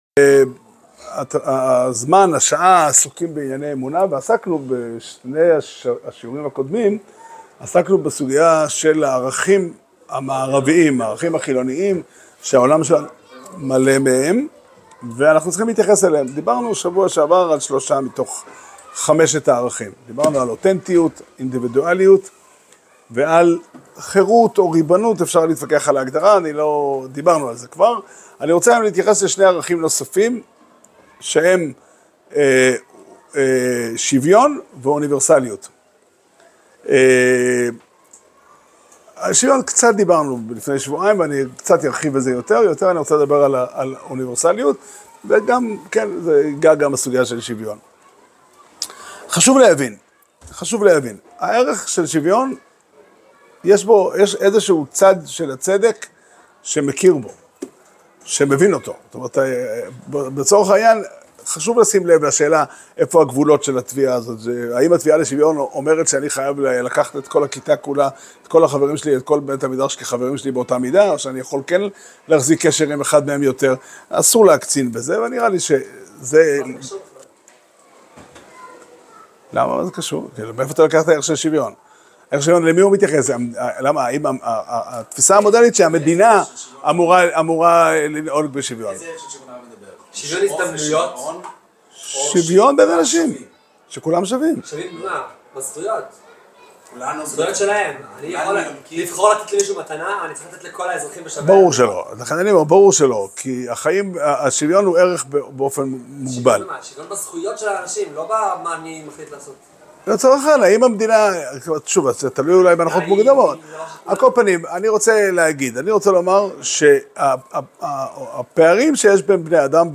שיעור שנמסר בבית המדרש פתחי עולם בתאריך י' כסלו תשפ"ה